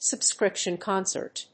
アクセントsubscríption còncert